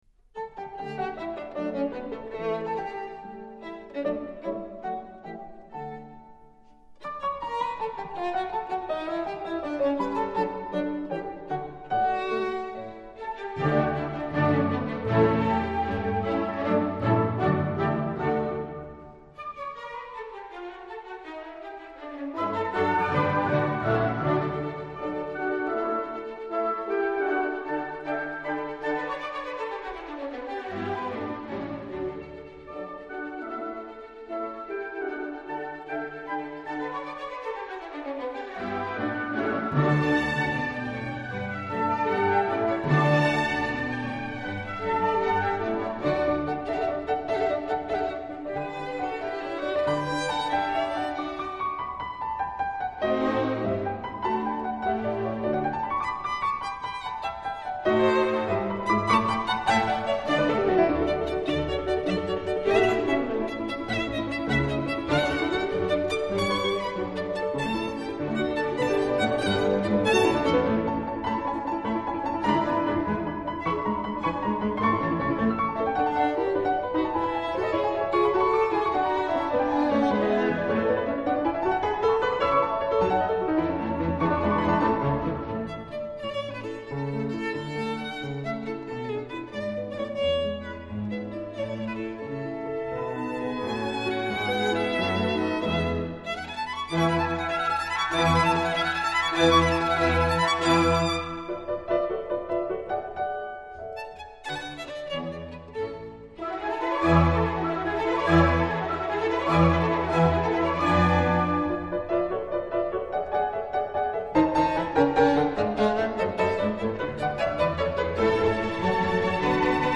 in D major - Andante